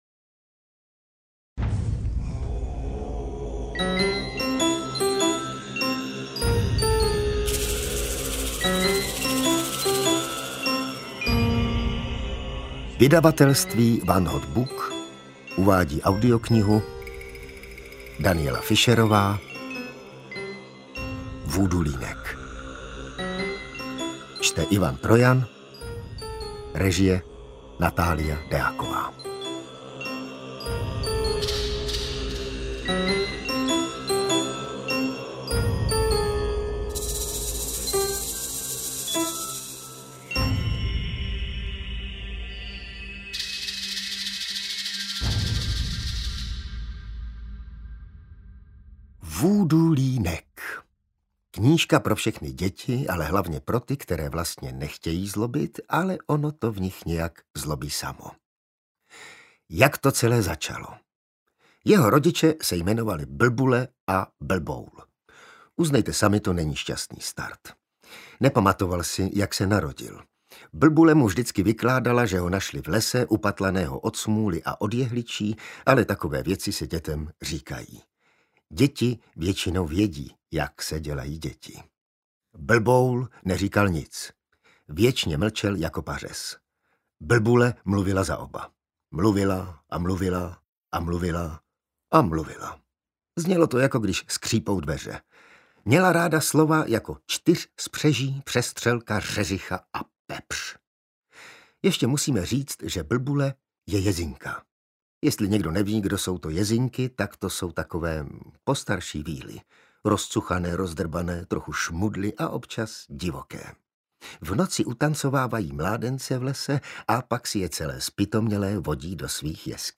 Vúdúlínek audiokniha
Ukázka z knihy
• InterpretIvan Trojan